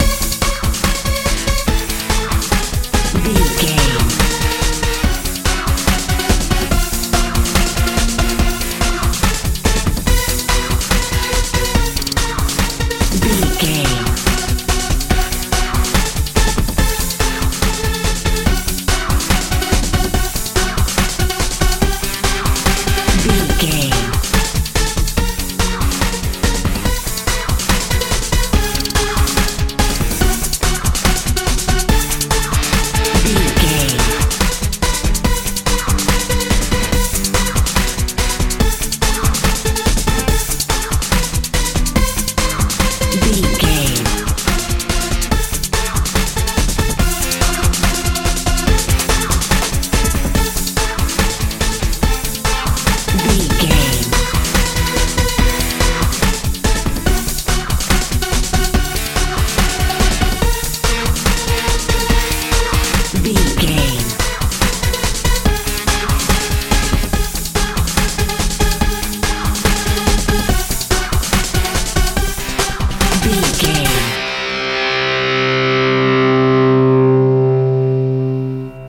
modern dance feel
Aeolian/Minor
Fast
energetic
dramatic
electric guitar
bass guitar
synthesiser
drums
80s
tension
suspense
strange